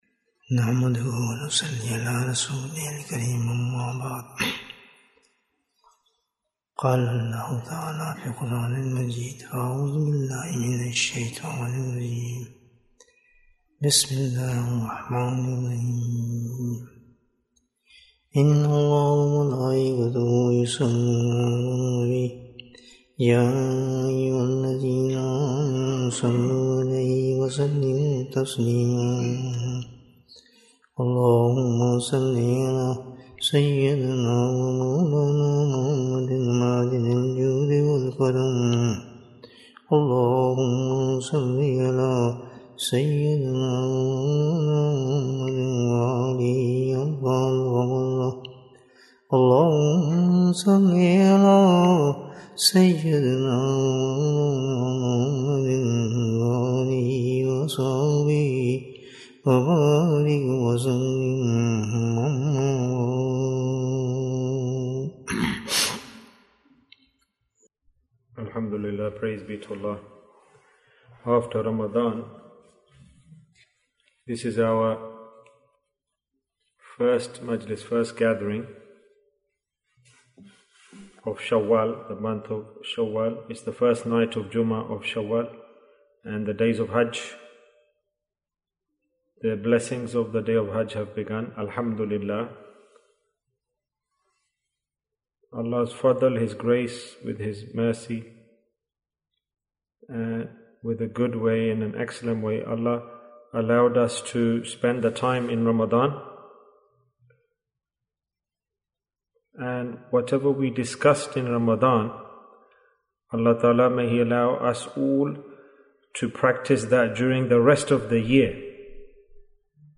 What is the Spirit of Tasawwuf? Bayan, 47 minutes27th April, 2023